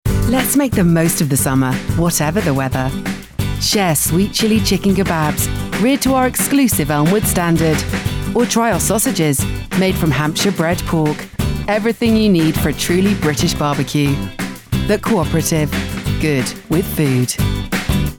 30/40's Neutral, Assured/Soothing/Husky
Commercial Showreel Calprofin North Face